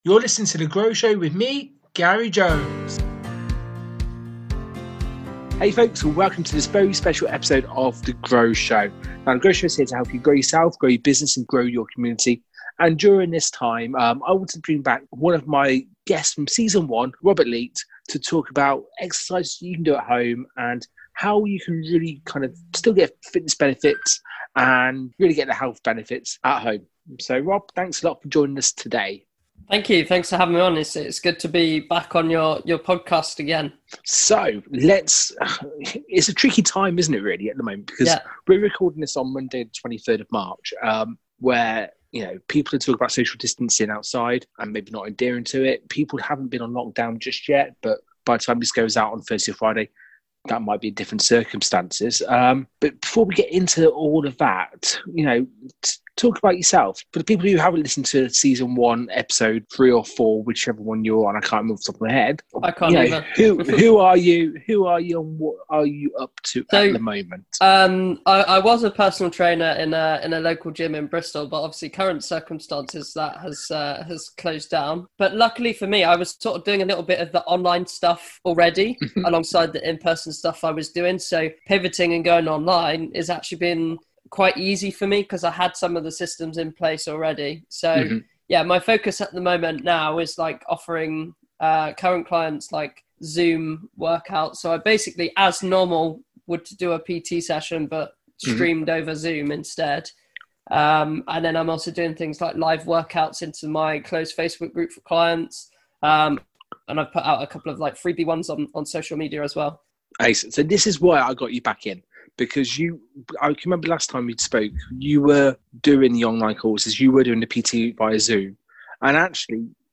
This is an honest, straight forward interview that if you are remote working at the moment due to COVID-19, you need to listen to!